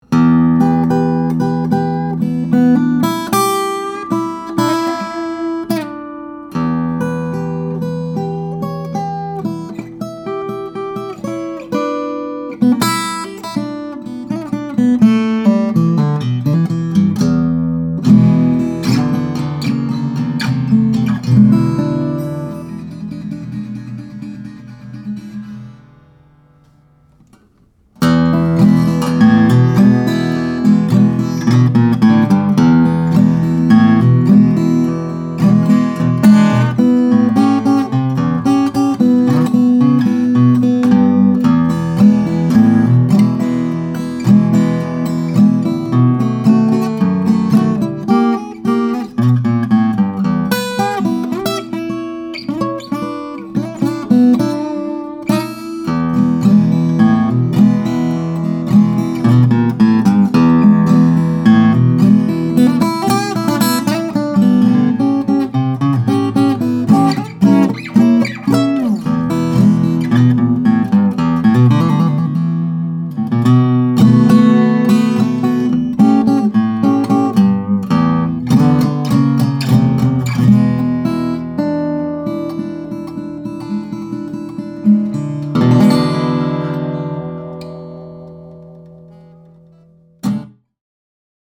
2011 Martin 00-45SC John Mayer Stagecoach Edition, Cocobolo/Adirondack Spruce - Dream Guitars